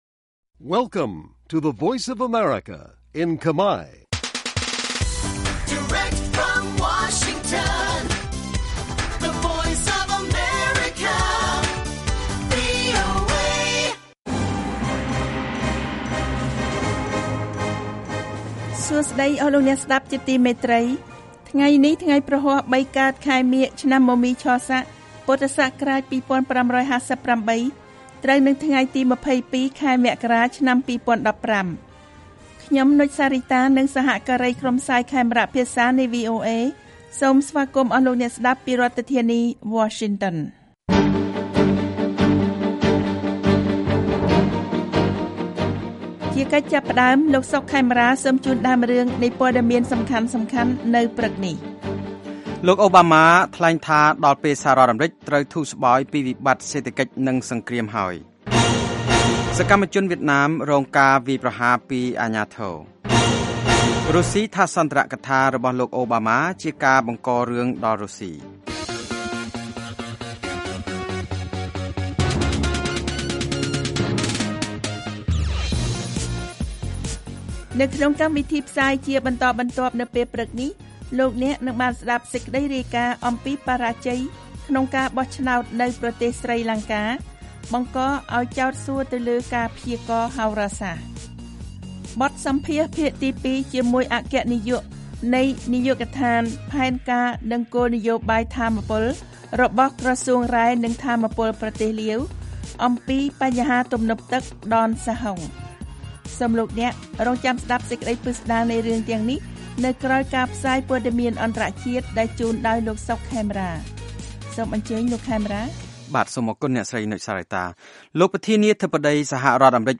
This daily 30-minute Khmer language radio program brings news about Cambodia and the world, as well as background reports, feature stories, and editorial, to Khmer listeners across Cambodia.